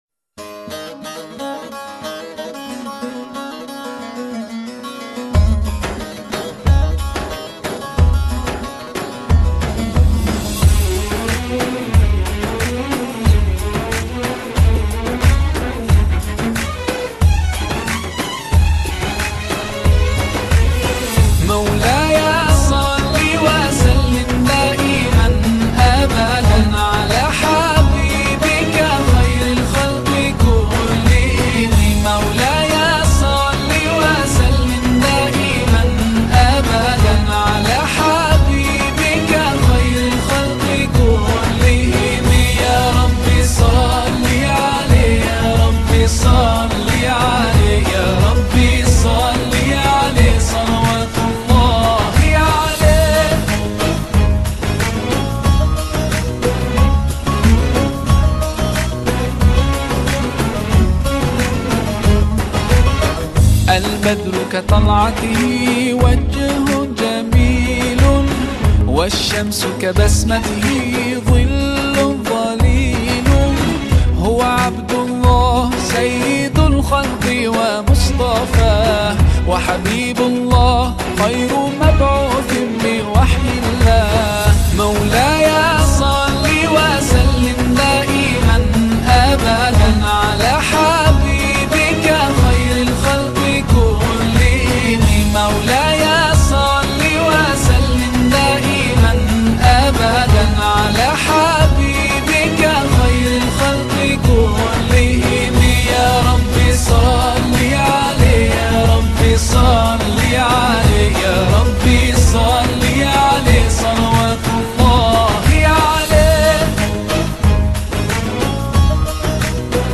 Genre Musik                            : Religi, Sholawat